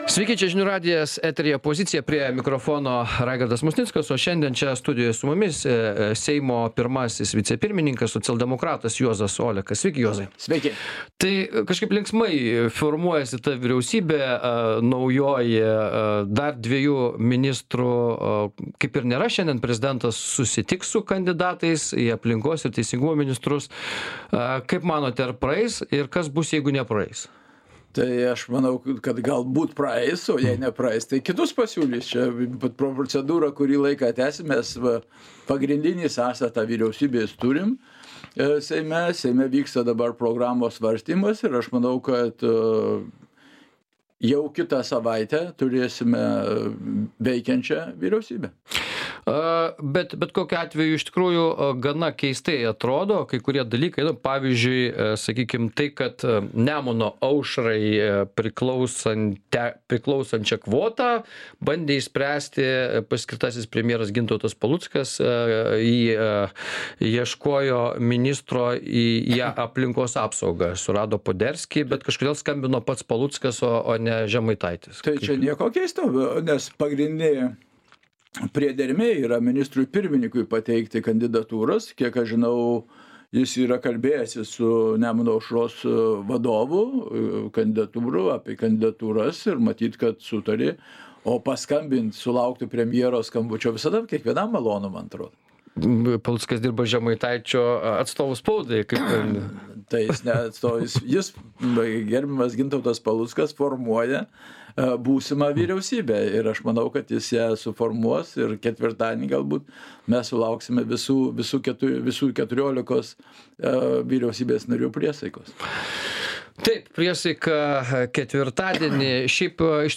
Laidoje dalyvauja Seimo vicepirmininkas, socialdemokratas Juozas Olekas.